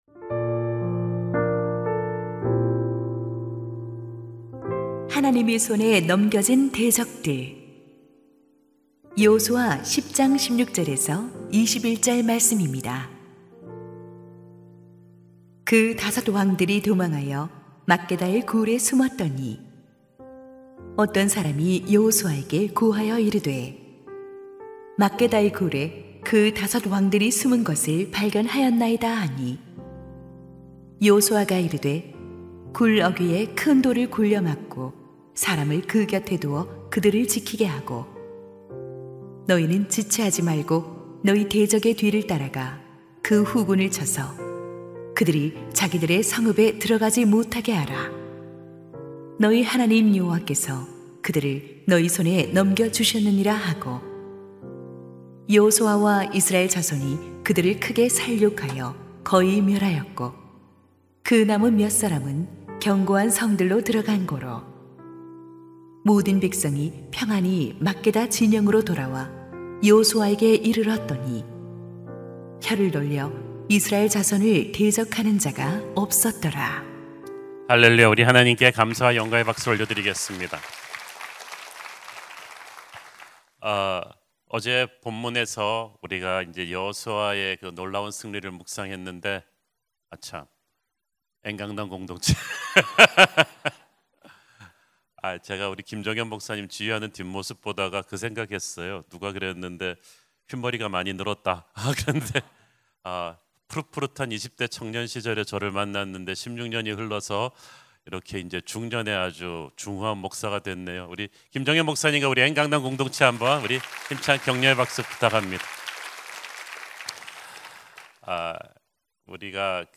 [새벽예배]